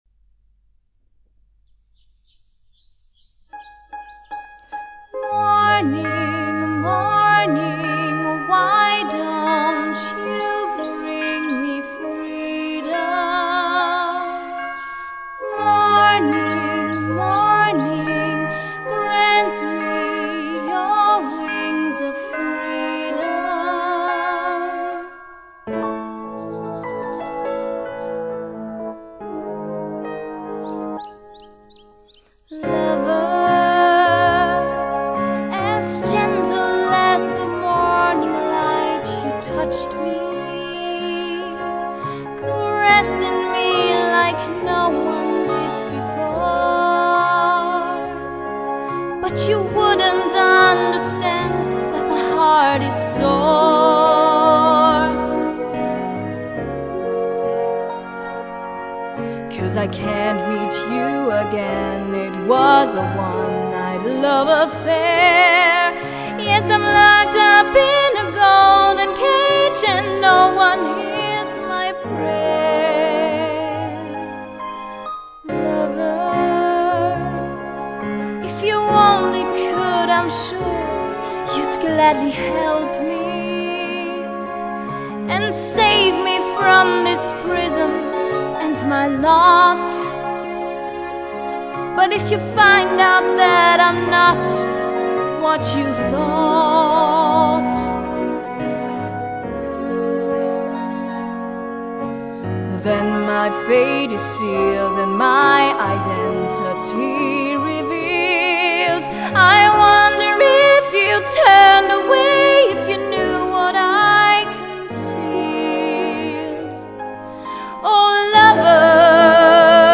Das Schloß, am Söller steht Princess Trivia und singt: